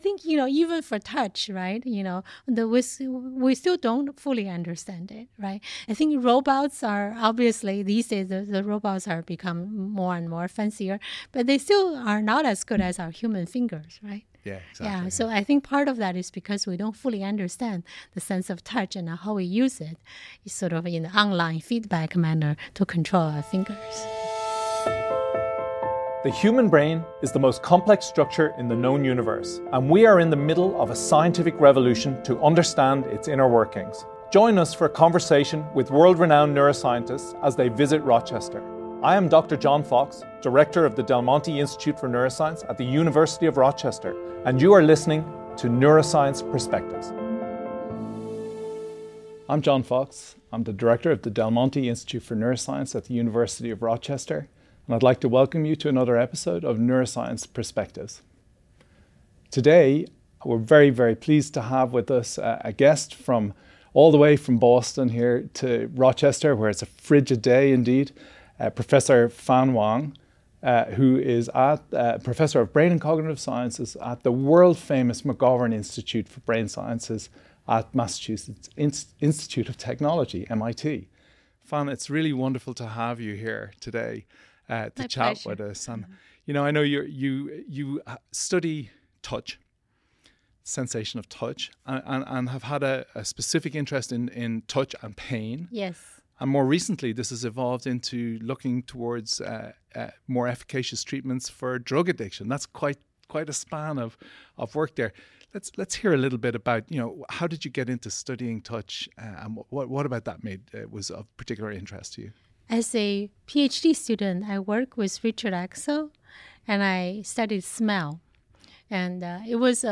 in Chicago at the annual Society for Neuroscience (SfN) conference